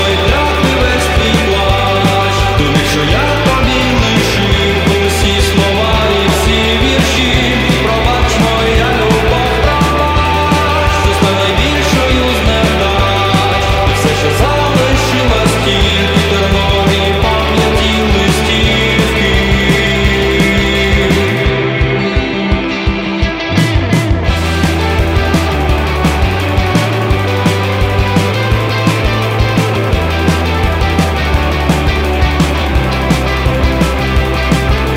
New Wave Alternative
Жанр: Альтернатива / Украинские